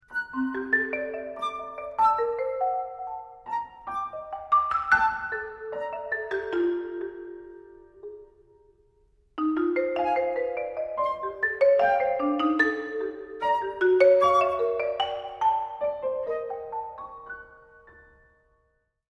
flute
cello
piano